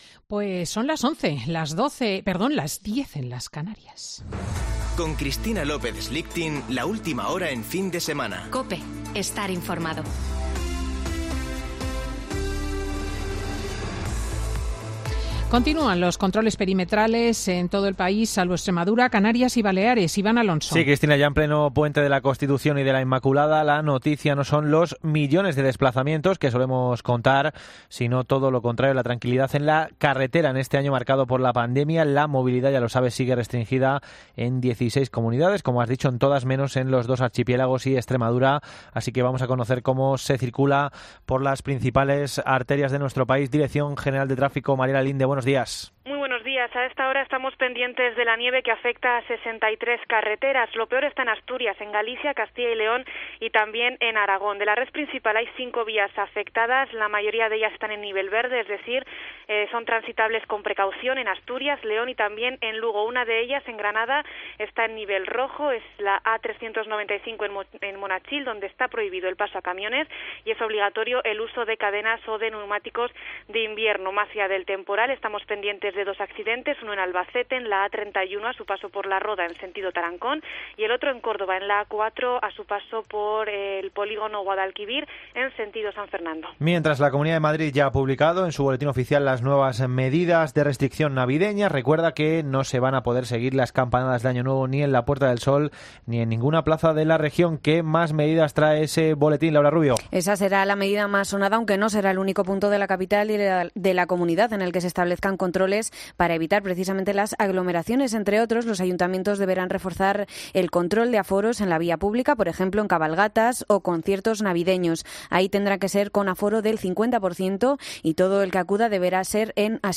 Boletín de noticias de COPE del 5 de diciembre de 2020 a las 11.00 horas